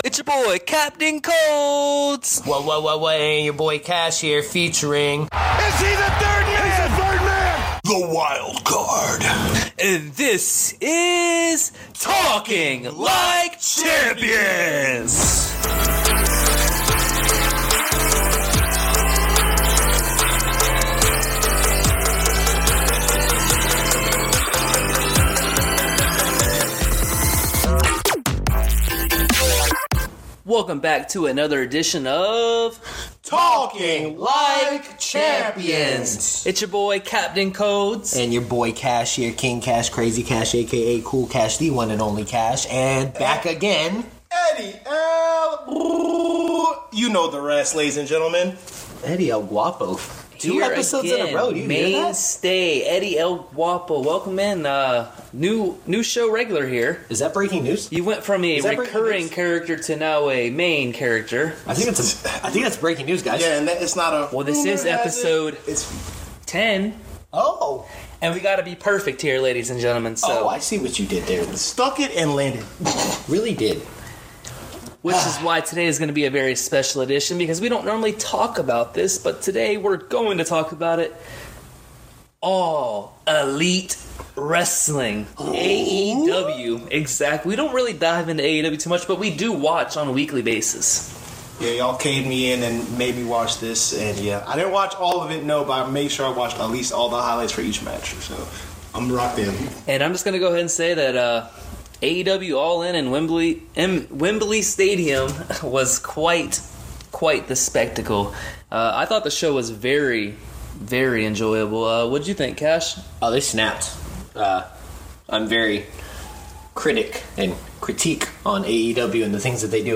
Talking Like Champions Wrestling Podcast is a podcast with 3 good friends who share the same love and passion for wrasslin'.